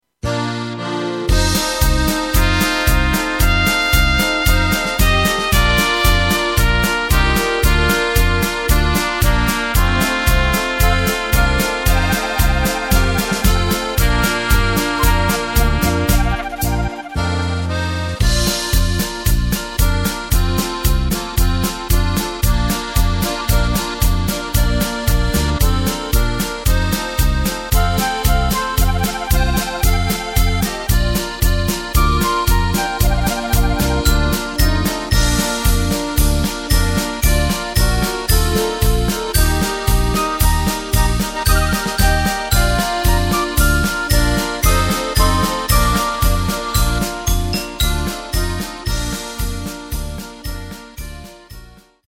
Tempo:         113.50
Tonart:            Bb
Marsch-Lied!
Playback mp3 Demo